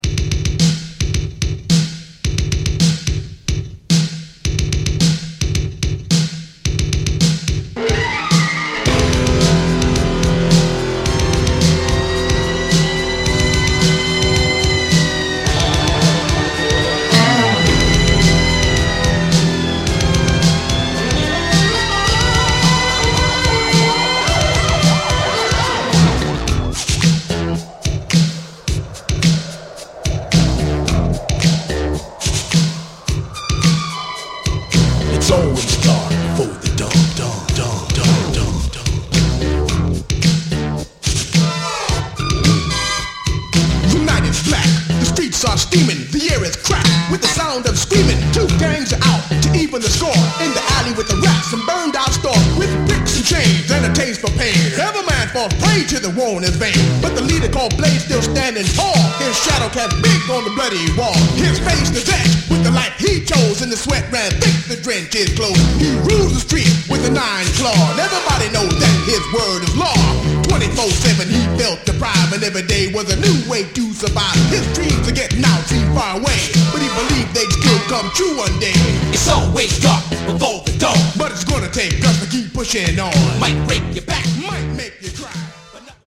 Obscure 12 from the masters of the Rap!